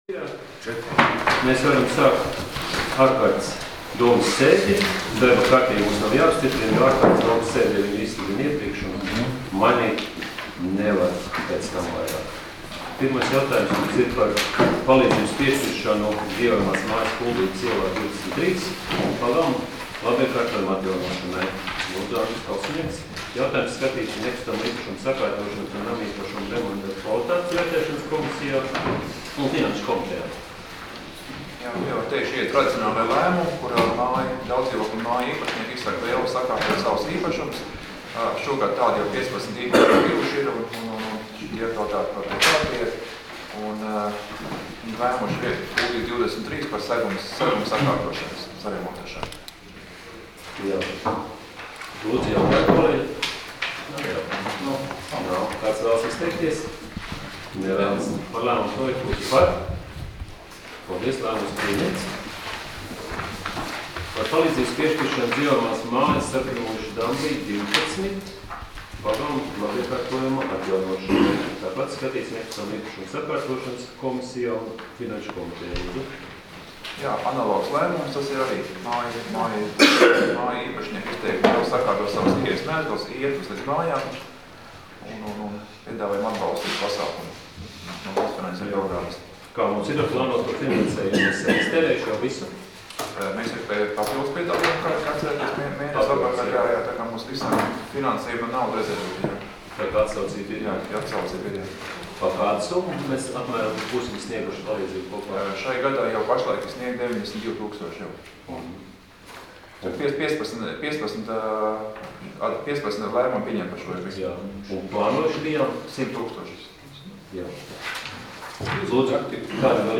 Domes sēdes 21.10.2016. audioieraksts